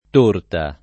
torta [ t 1 rta ] s. f. («dolce») — solo con -o- chiuso la pn.